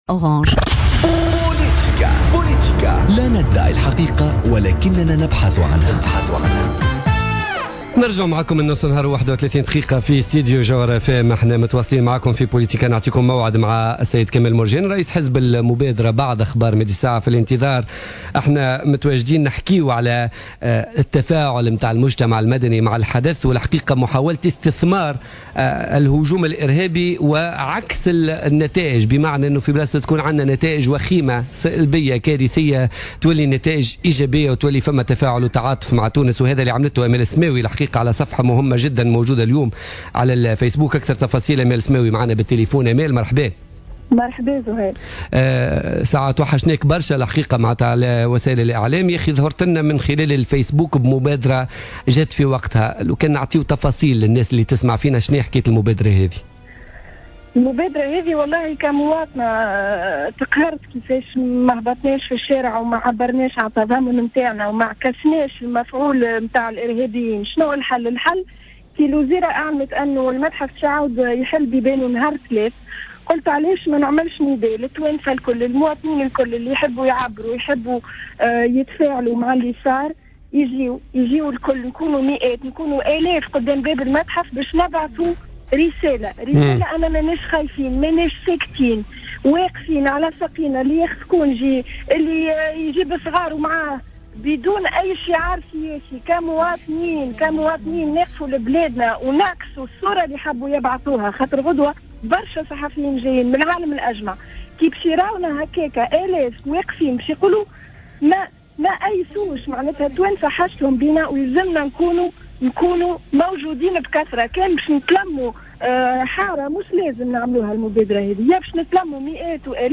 في مداخلة لها في برنامج بوليتيكا